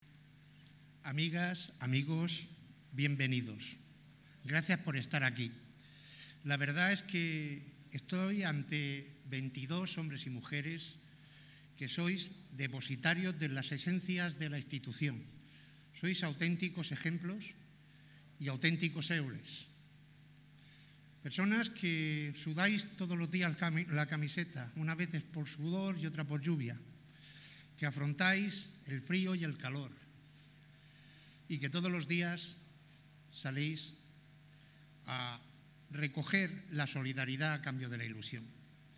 en su discurso de bienvenida a los asistentes a la gala ‘Buena Gente ONCE’